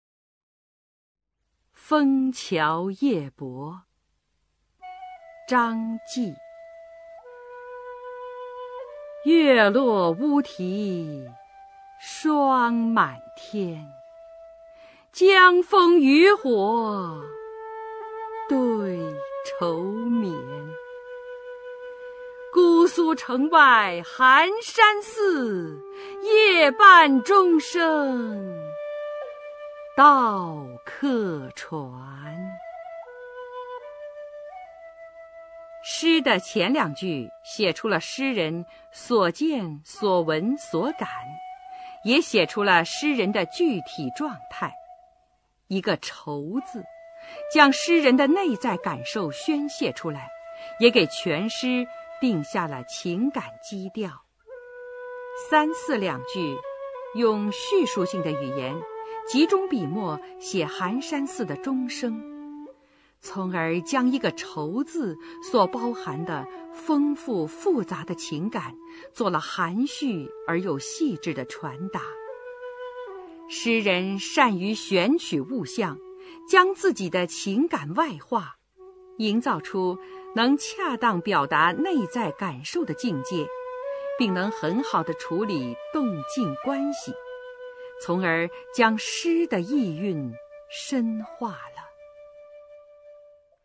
[隋唐诗词诵读]张继-枫桥夜泊（女） 配乐诗朗诵